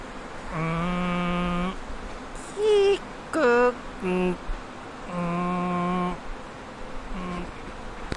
Tag: 中环站 纹理 吉隆坡 吉隆坡